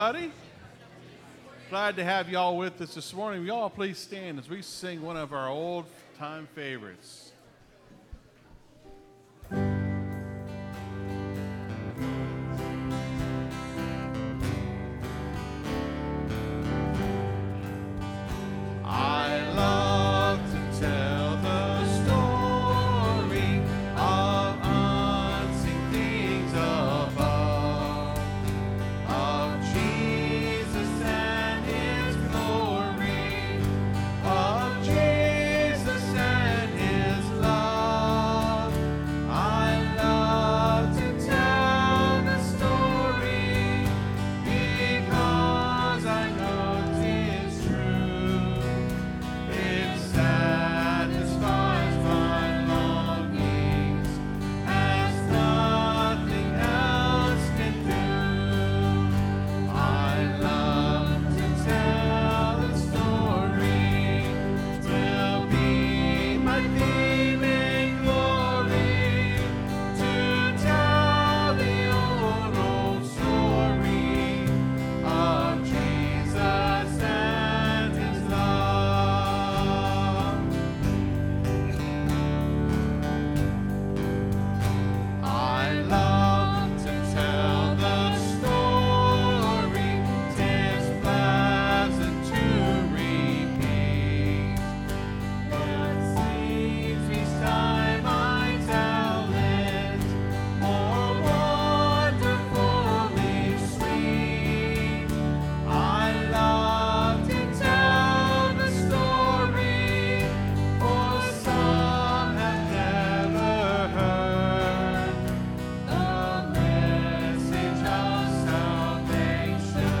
(Sermon starts at 27:30 in the recording).